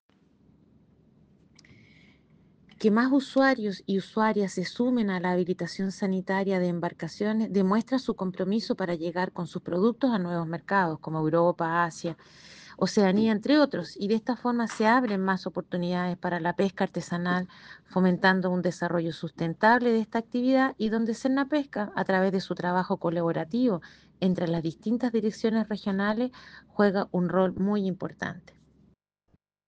La directora regional de Sernapesca Coquimbo, Cecilia Solís, valoró la disposición de los pescadores para incorporarse al programa “Que más usuarios y usuarias se sumen a la habilitación sanitaria de embarcaciones demuestra su compromiso para llegar con sus productos a nuevos mercados.